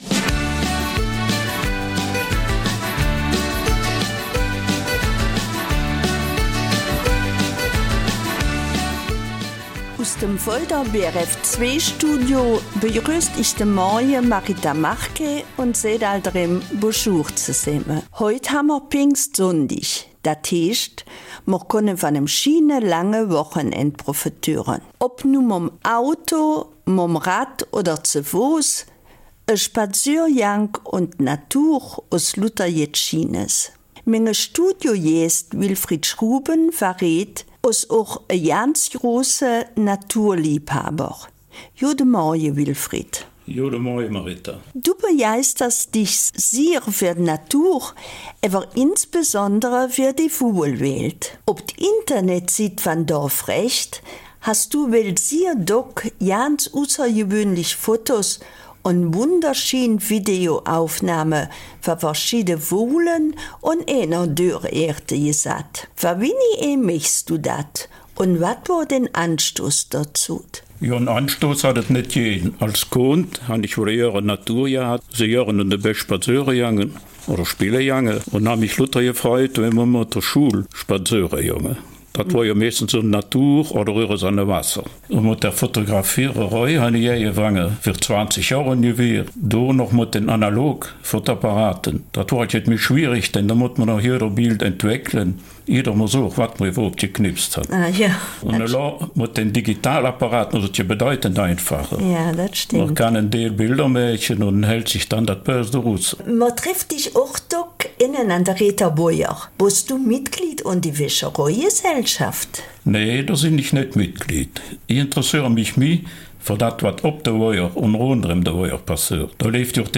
Eifeler Mundart: Von Wildbienen und anderen Tieren